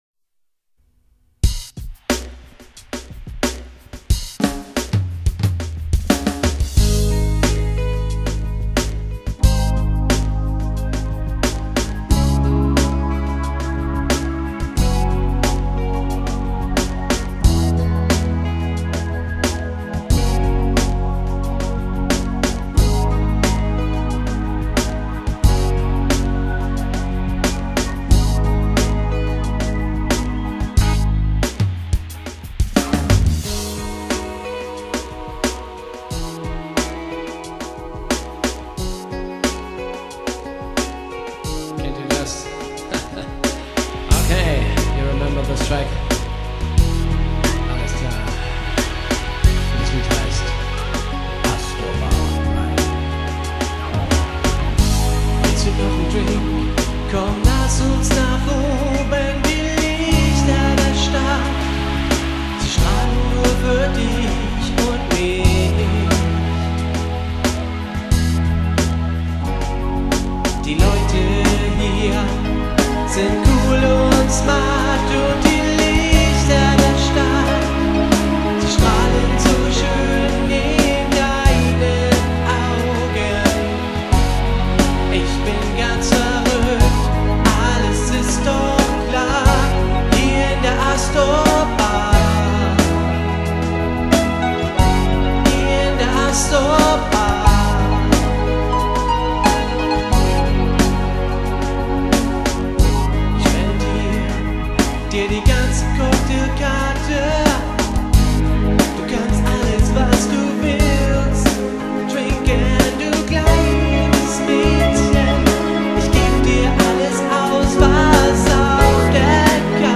ASTOR BAR KIEL live